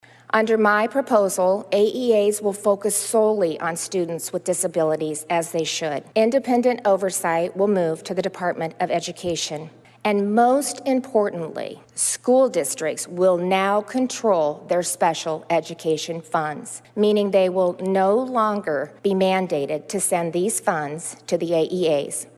Gov. Kim Reynolds discussed more proposed reforms she would like to implement for Iowa education during the Condition of the State address on Tuesday evening.